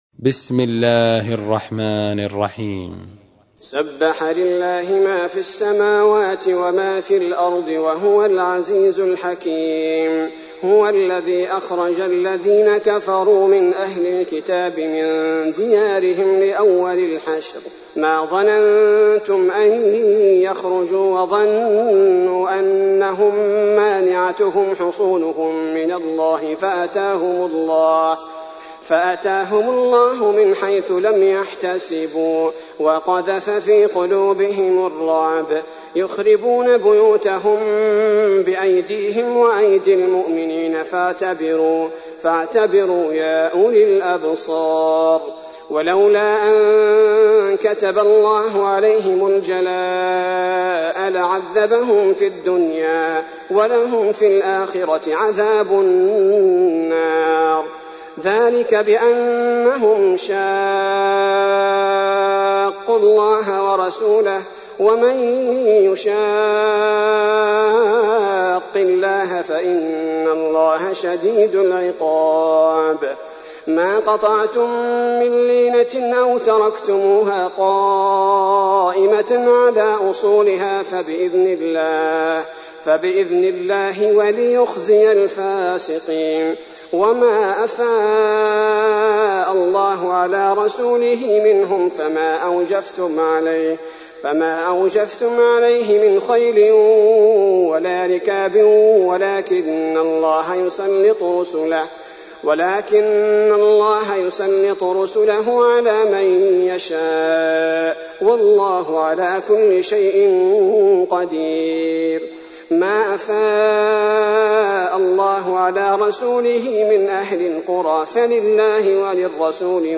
تحميل : 59. سورة الحشر / القارئ عبد البارئ الثبيتي / القرآن الكريم / موقع يا حسين